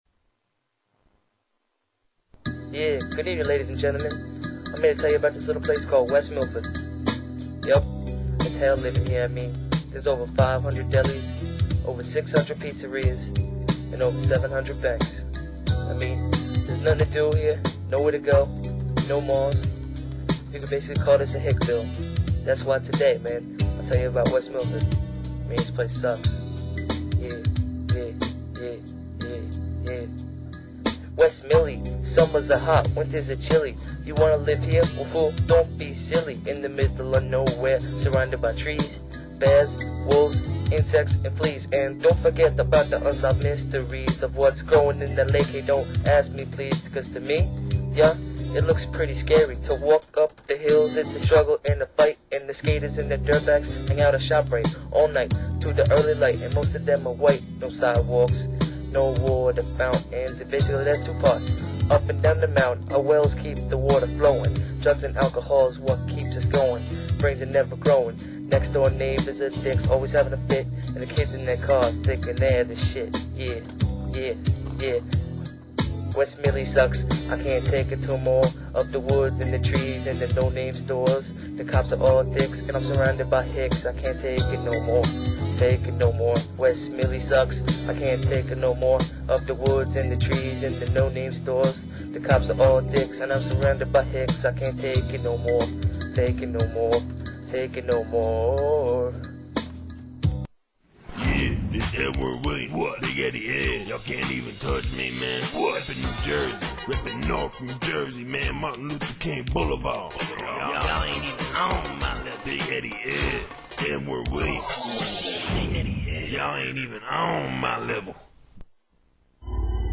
Originally broadcast June 21, 2005 on RadioBBQ.